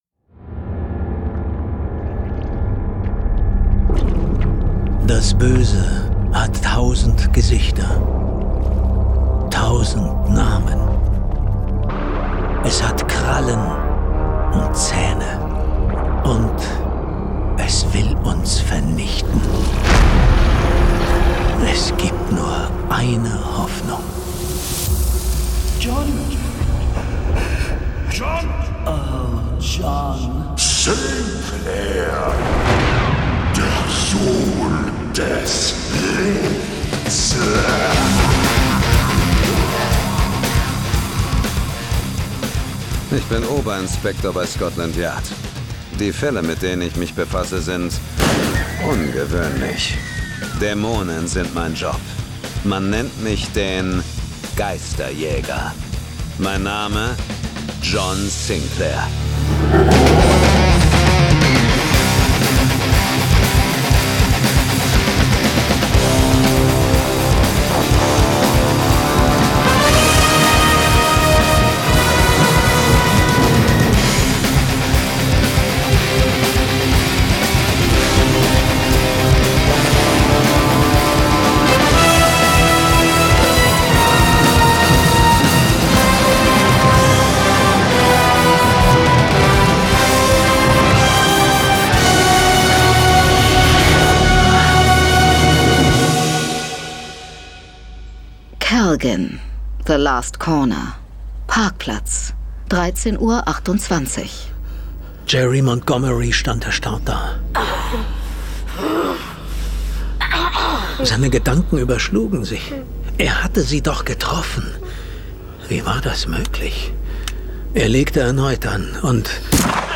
John Sinclair - Folge 183 Das Hexenschiff. Hörspiel. Jason Dark (Autor) Dietmar Wunder , diverse (Sprecher) Audio-CD 2025 | 1.